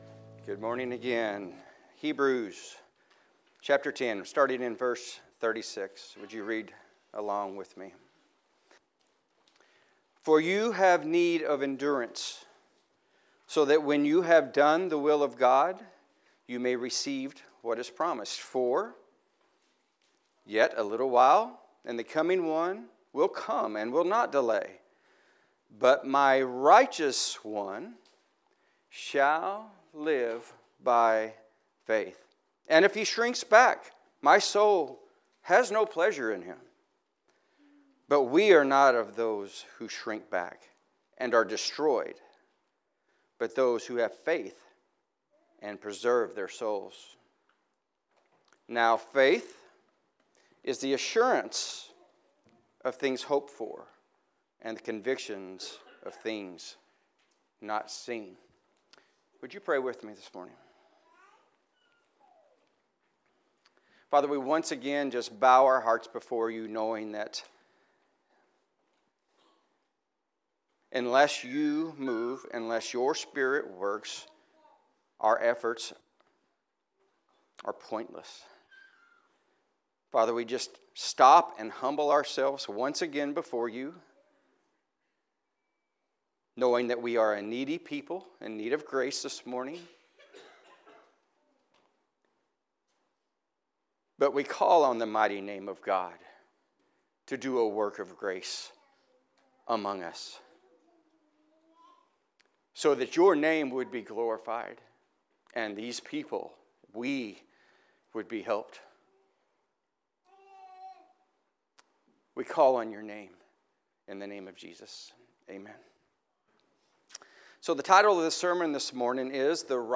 Passage: Hebrews 10:36-11:1 Service Type: Sunday Morning